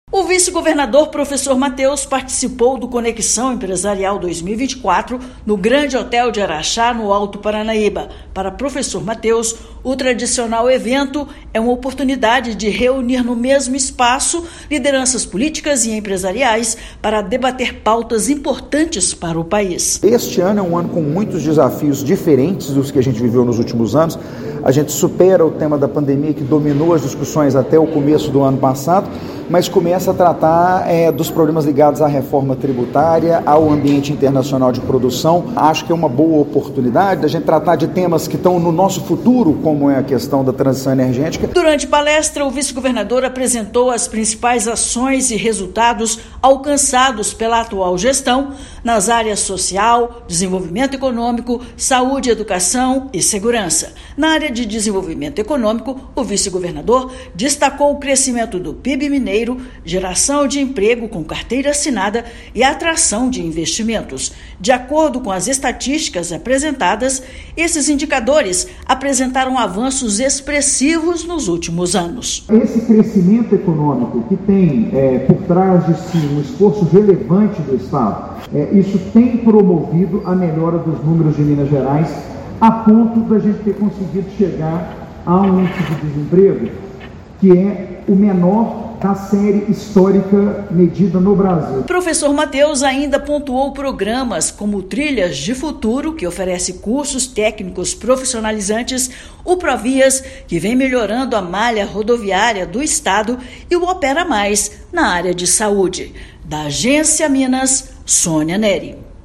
Evento anual realizado na cidade de Araxá, no Alto Paranaíba, reúne lideranças nacionais, estaduais e municipais para debater a situação do país. Ouça matéria de rádio.